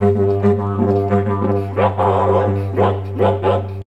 TALKING ST.wav